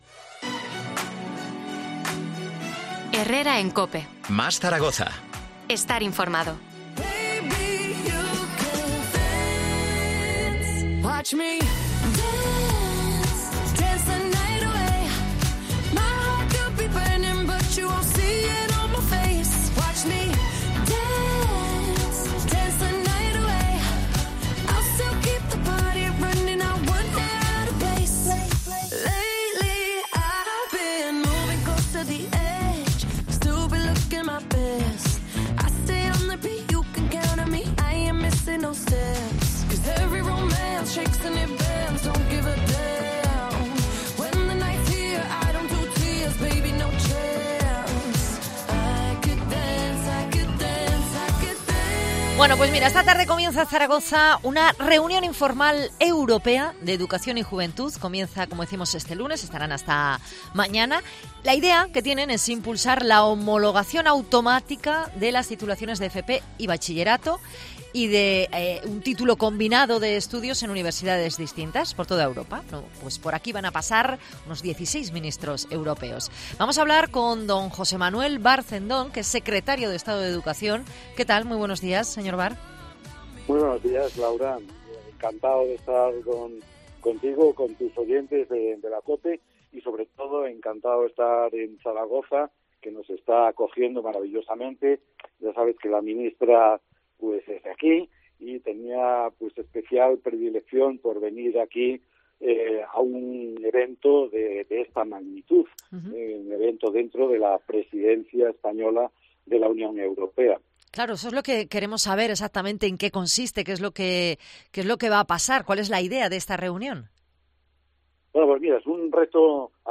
Entrevista al Secretario de Estado de Educación, José Manuel Bárceno.